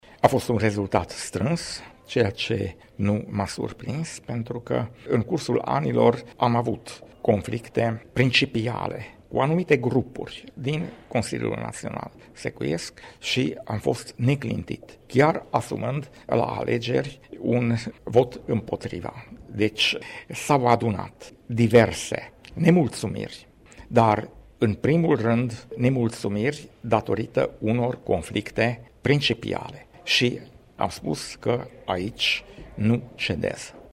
Izsák Balázs  a afirmat că în cadrul Congresului de la Tîrgu Mureș a avut parte de o competiție care i-a dat oarecare emoții, întrucât aceste alegeri au avut o miză morală foarte mare: